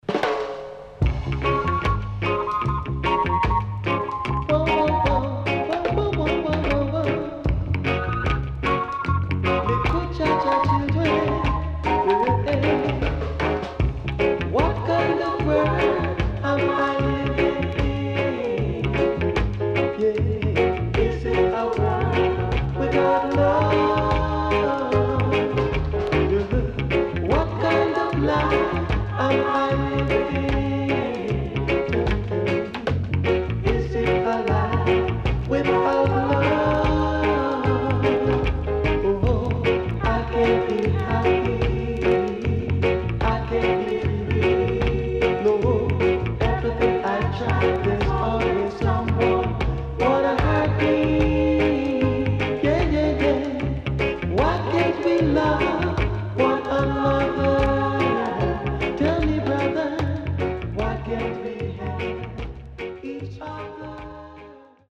Reissue Used-【10inch】
SIDE A:少しチリノイズ入りますが良好です。